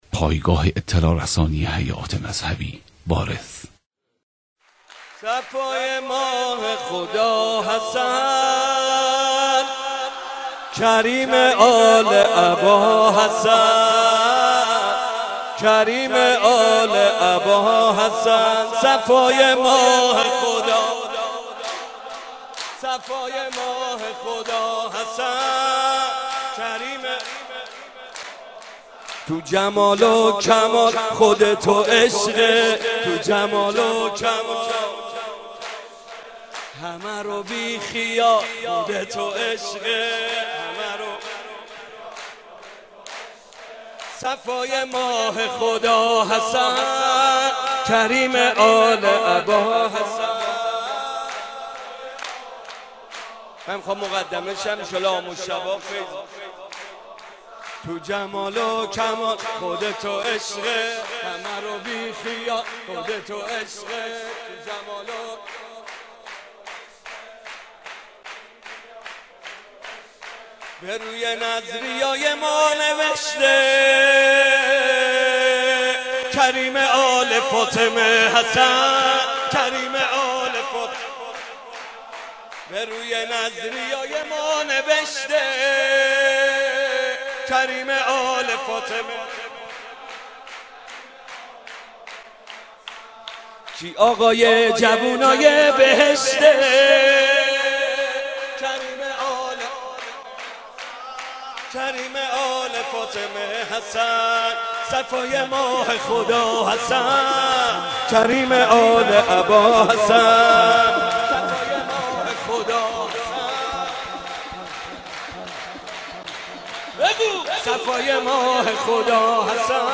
مولودی حاج محمد رضا طاهری به مناسبت میلاد با سعادت امام حسن مجتبی (ع)
هیئت مکتب الزهرا س